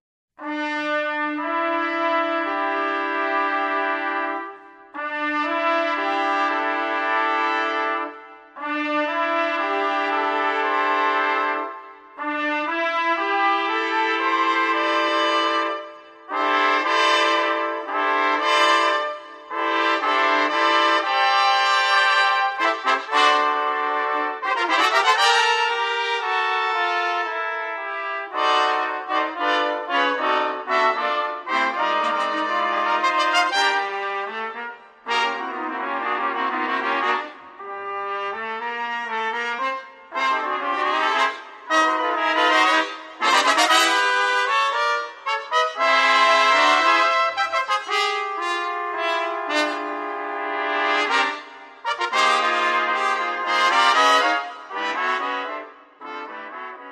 quintette de trompettes (05'26)
** Studio BOLO.